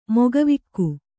♪ mogavikku